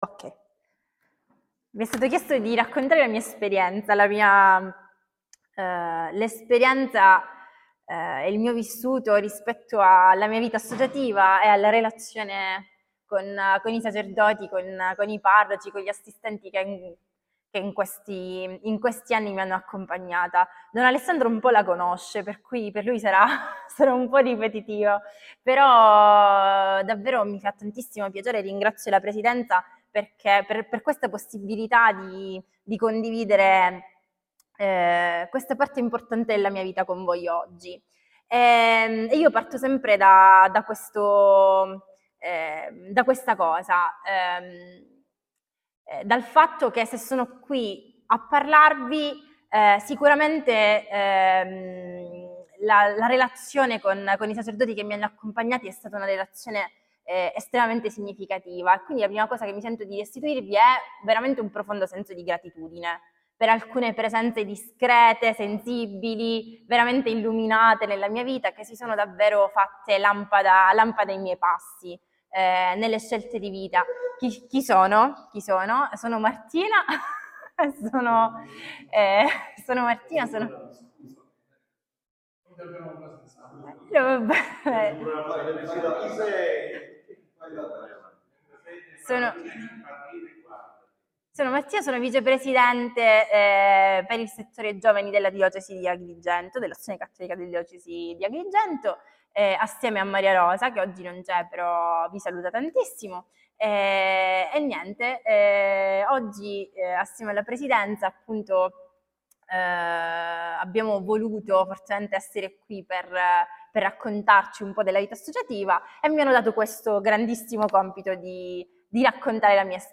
Si è tenuto il 27 maggio 2023, nella “Sala Giovanni Paolo II” del Palazzo Arcivescovile di Agrigento, l’incontro tra l’Arcivescovo, mons. Alessandro Damiano, la presidenza diocesana dell’Azione Cattolica e gli assistenti parrocchiali.